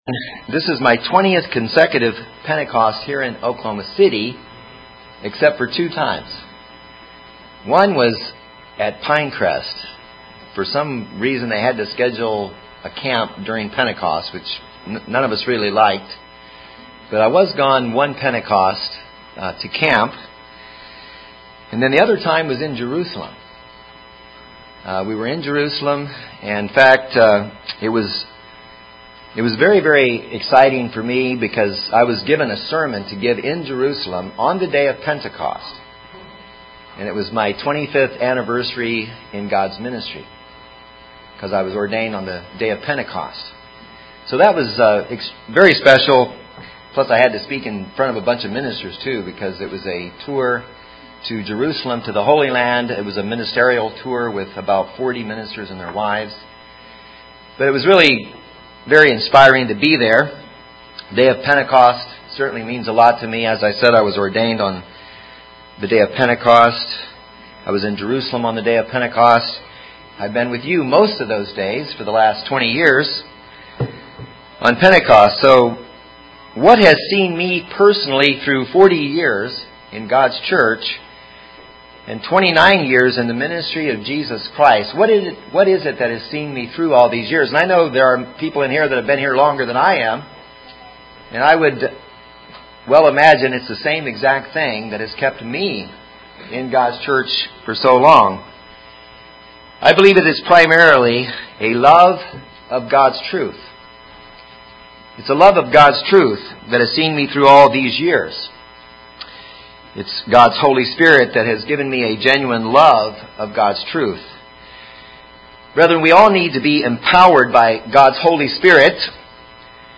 What is a genuine love of the truth, and how may we be empowered by God's Holy Spirit to love His truth? This sermon was given on Pentecost.